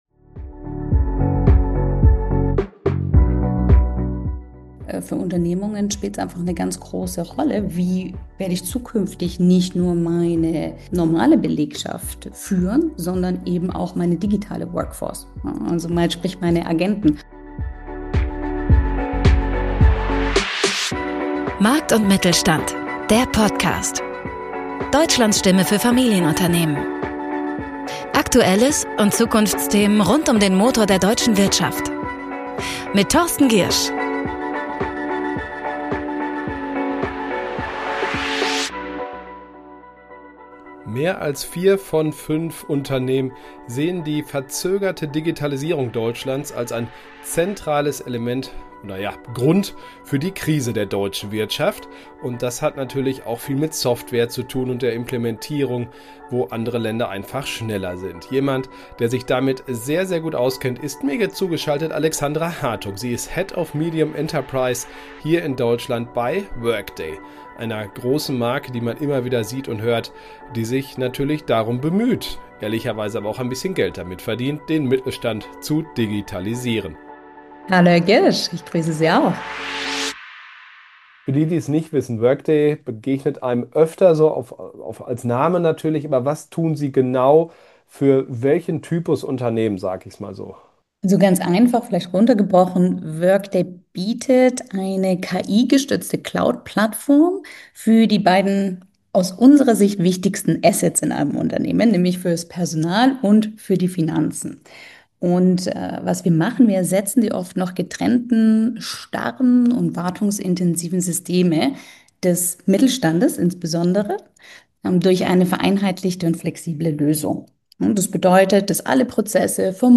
Wir sprechen mit einem Gast zu einem für den Mittelstand wichtigen Thema – hoher Nutzwert garantiert! Unser Ziel ist, (potenzielle) Führungskräfte in mittelständischen Unternehmen auf Ideen zu bringen, wie sie ihr Unternehmen zukunftsfester machen können.